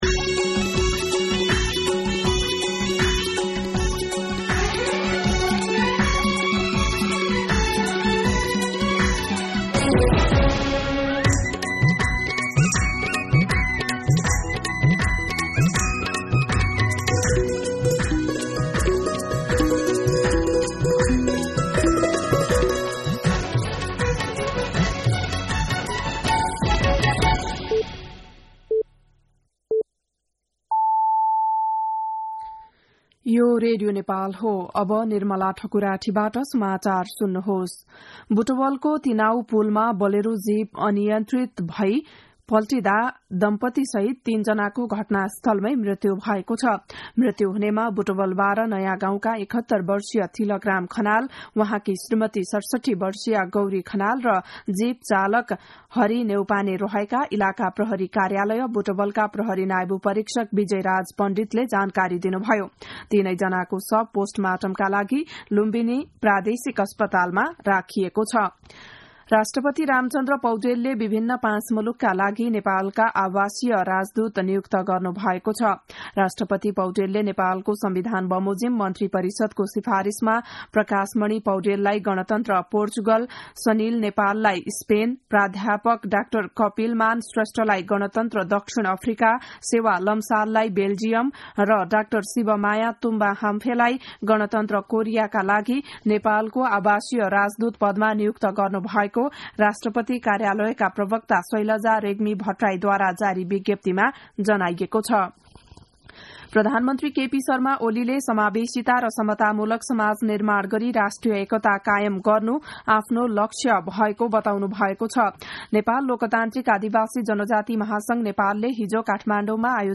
बिहान ११ बजेको नेपाली समाचार : ३० मंसिर , २०८१
11-am-Nepali-News.mp3